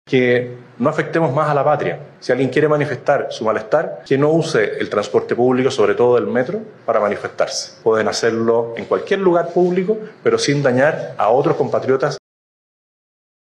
En tanto, mientras se desarrollaba la jornada de movilización, el Presidente Kast aprovechó el acto de promulgación de la ley de Emergencia Energética, “Chile Sale Adelante”, para hacer un llamado a manifestarse de forma pacífica y sin violencia.